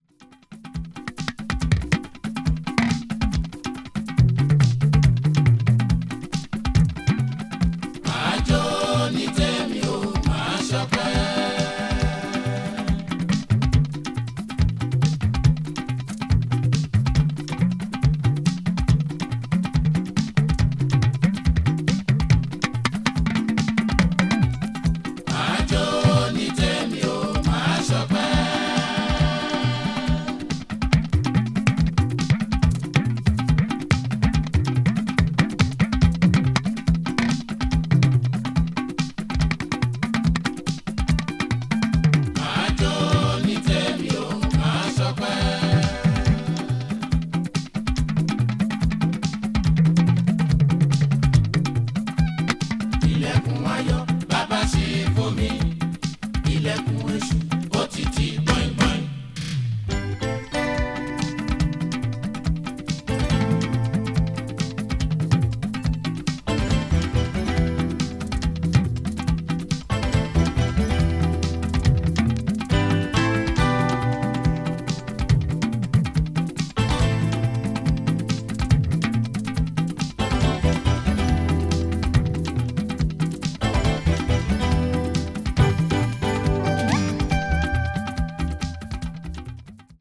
かなりハイピッチで演奏されていて、とてもダンサブル。例のごとくノンストップで疾走しています。
afro groove   juju   nigeria   west africa   world music